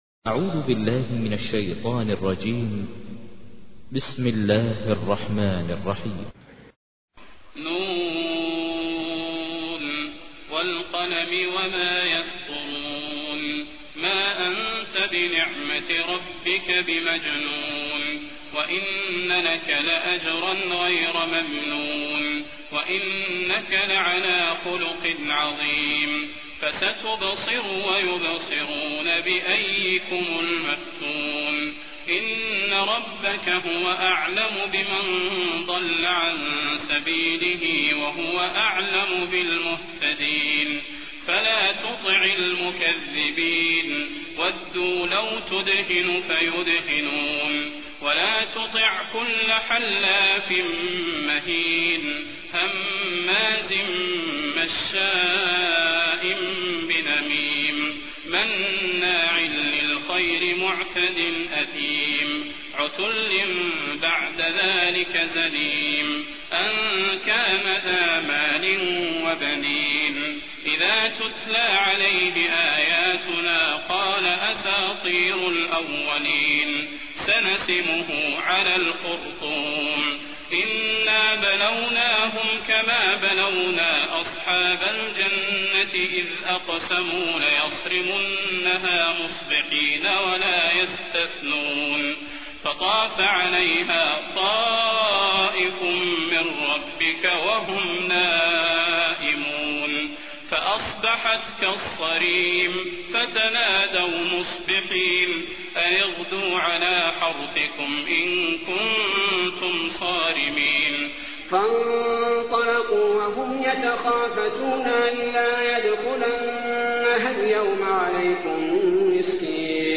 تحميل : 68. سورة القلم / القارئ ماهر المعيقلي / القرآن الكريم / موقع يا حسين